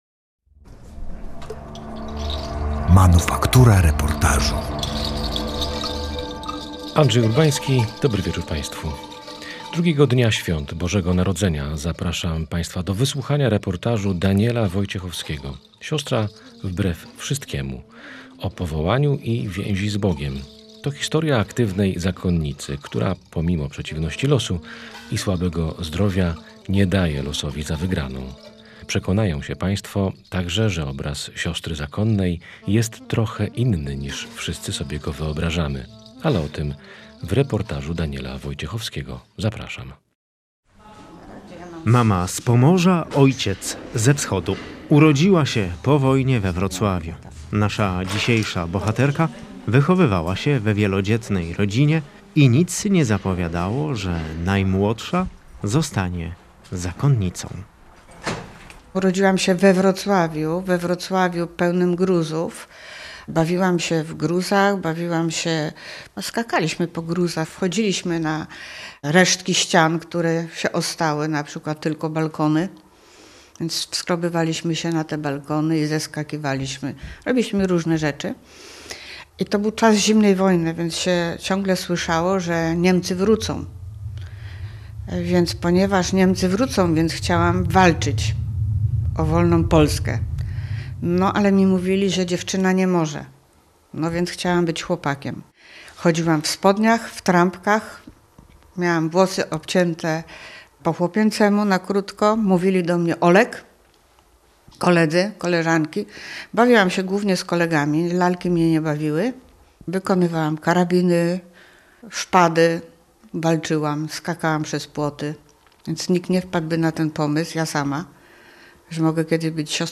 reportaż